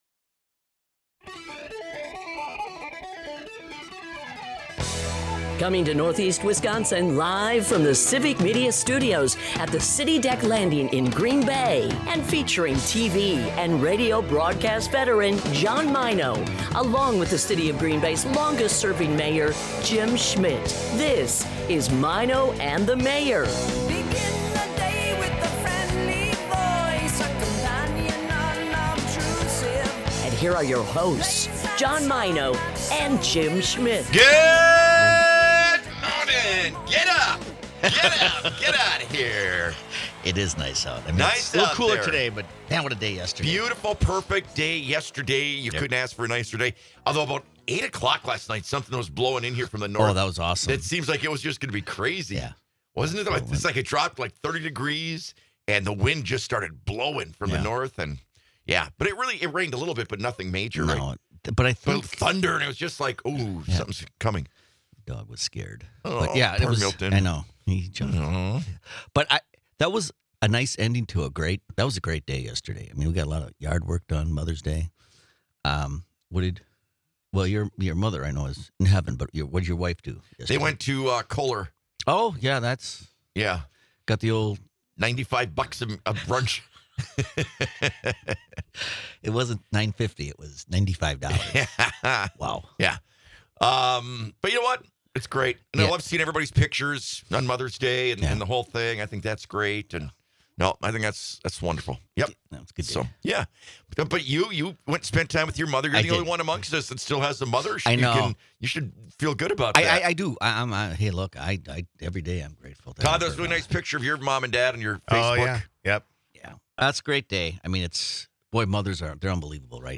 Experience the chemistry and humor between two great friends. Broadcasts live 6 - 9am in Oshkosh, Appleton, Green Bay and surrounding areas.